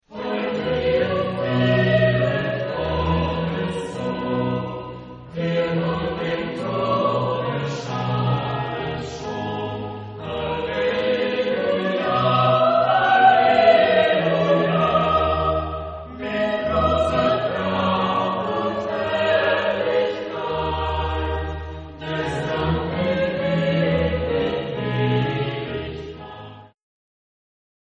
Genre-Stil-Form: Barock
Chorgattung: SATB  (4 gemischter Chor Stimmen )
Instrumente: Orgel (ad lib)